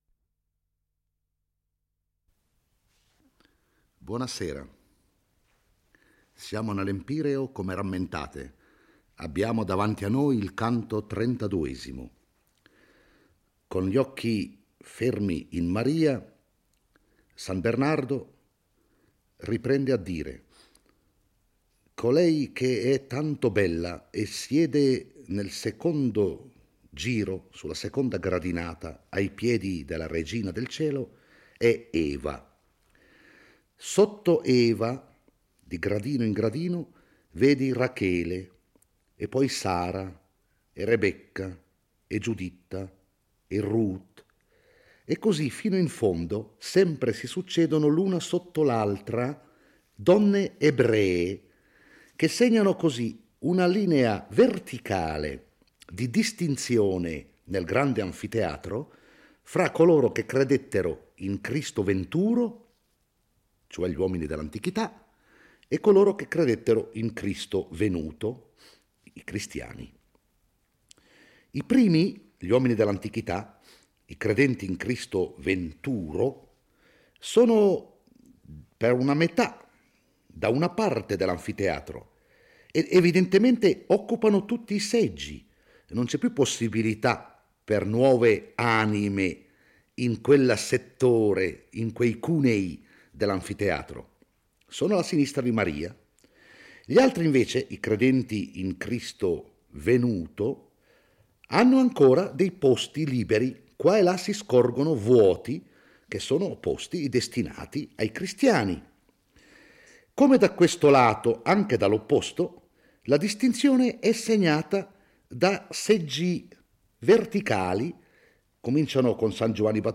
legge e commenta il XXXII canto del Paradiso.